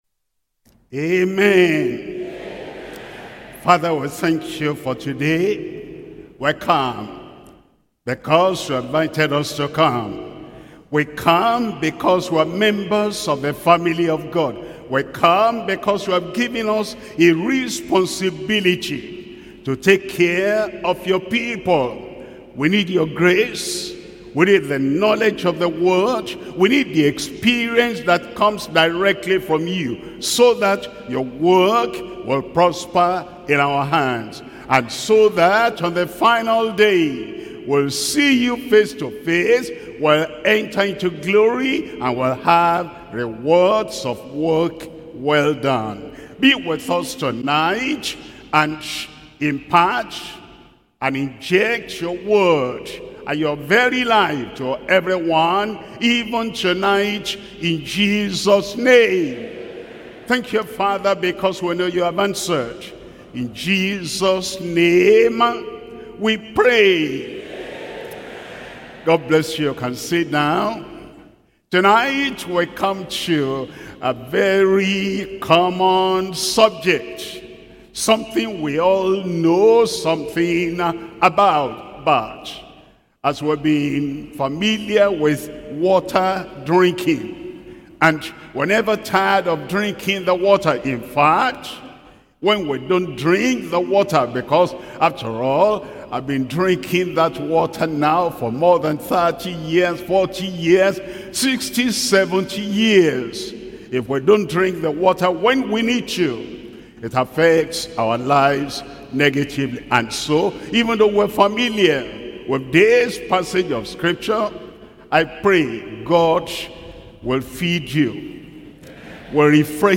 Sermons - Deeper Christian Life Ministry
2025 Workers Training